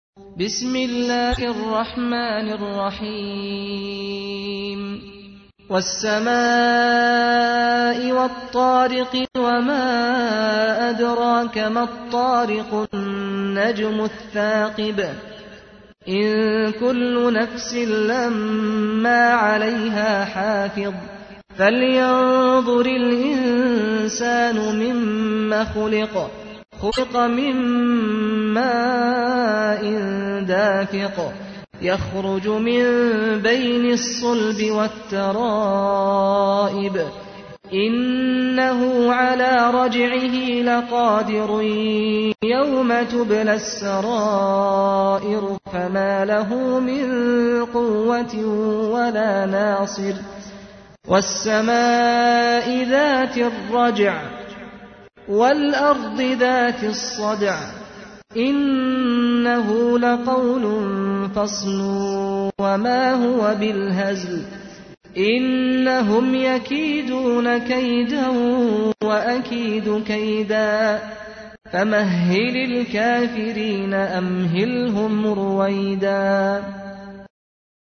تحميل : 86. سورة الطارق / القارئ سعد الغامدي / القرآن الكريم / موقع يا حسين